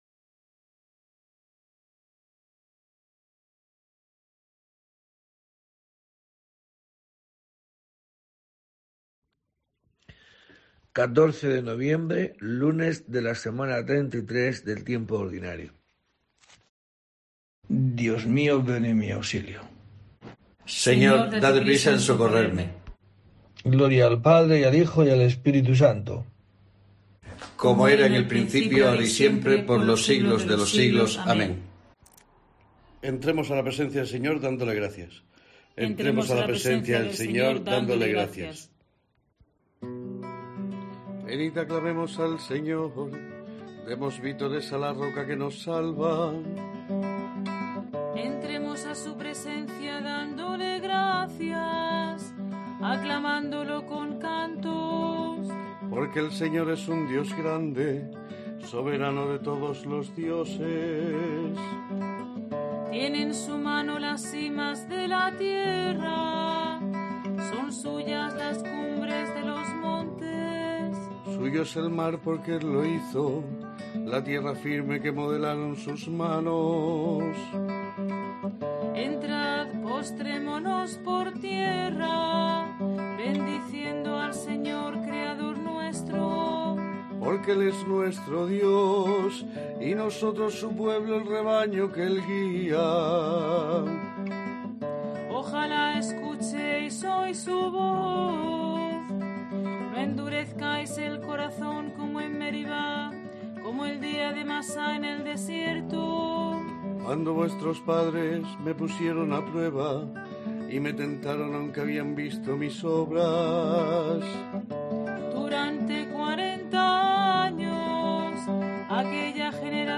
14 de noviembre: COPE te trae el rezo diario de los Laudes para acompañarte